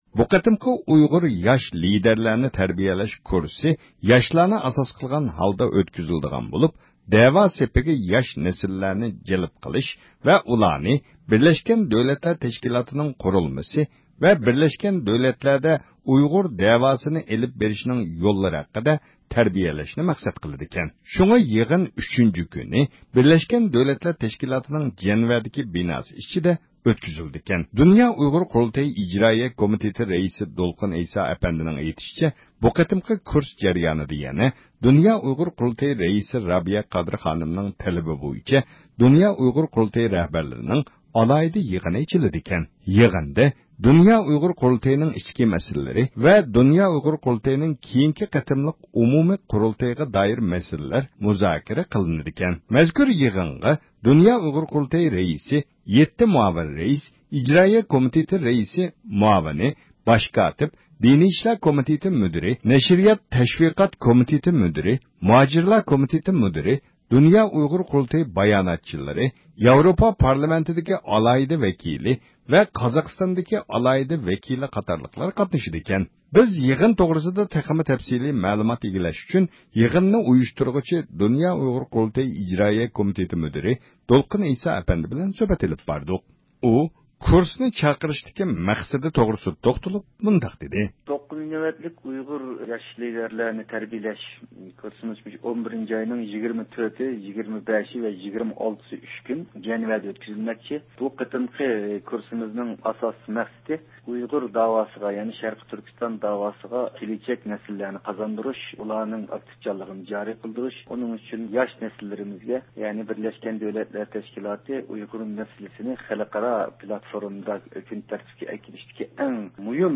بىز يىغىن توغرىسىدا تەپسىلىي مەلۇمات ئىگىلەش ئۈچۈن يىغىننى ئۇيۇشتۇرغۇچى د ئۇ ق ئىجرائىيە كومىتېتى مۇدىرى دولقۇن ئەيسا ئەپەندى بىلەن سۆھبەت ئېلىپ باردۇق.